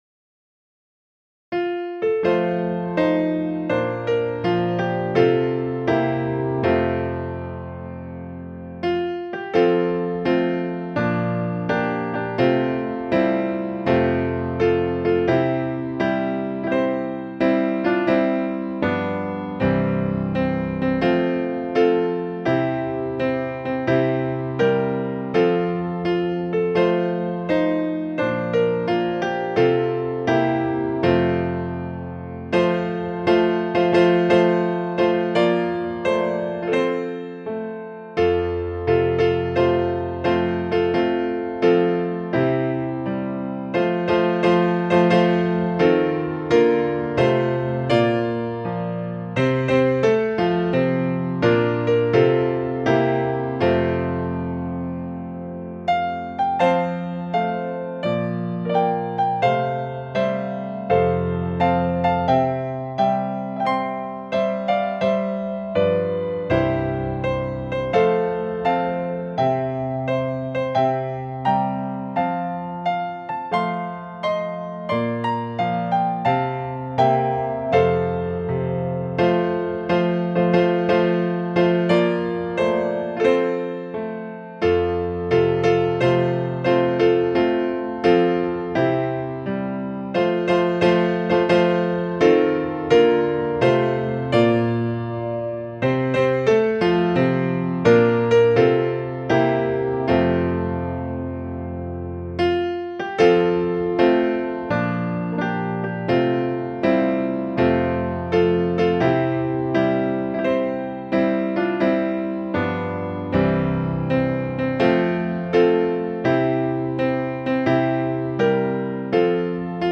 This entry was posted on Monday, May 11th, 2009 at 9:28 pm and is filed under hymns.